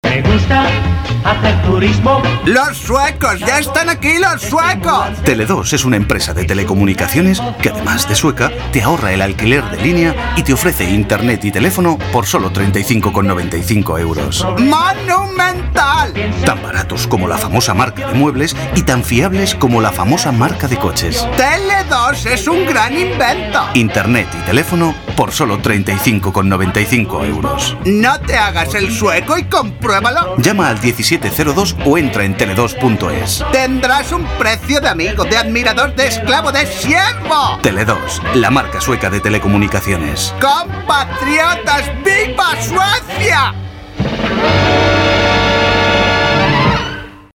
CUÑA_TELE2.mp3